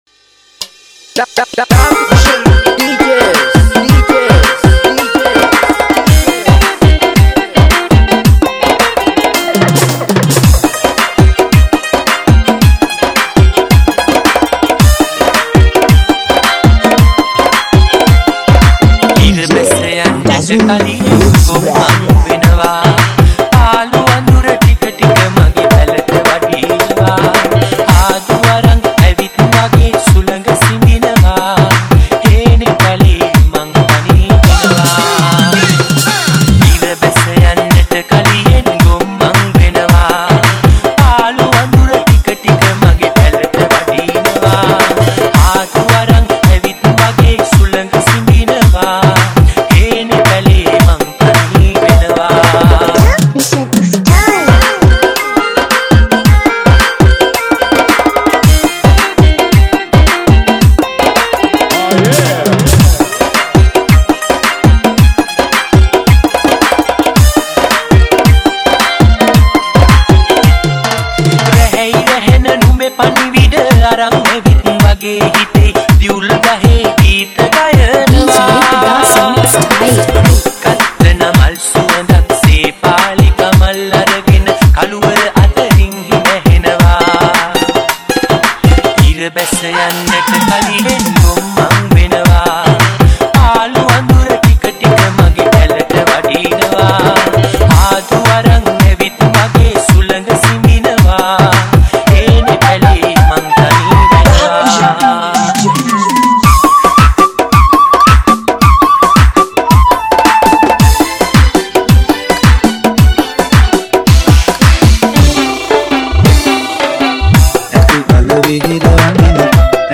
Category: Dj Remix